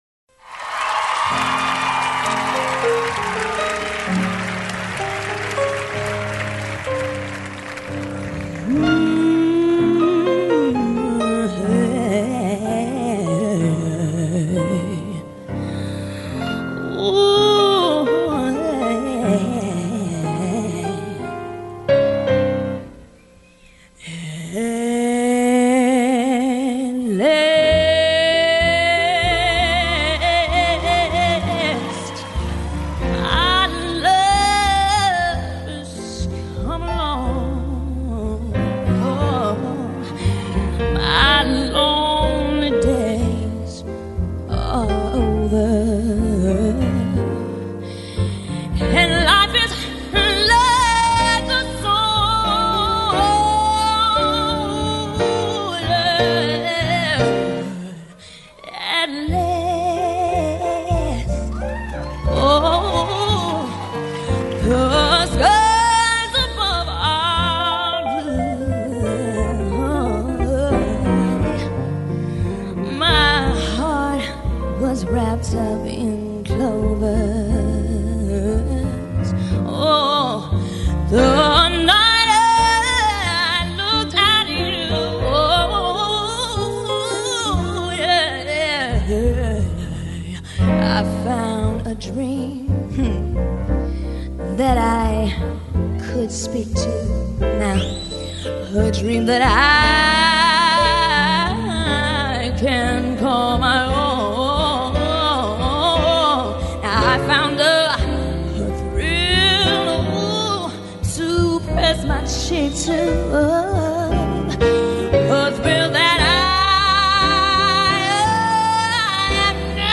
is an example of melisma on steroids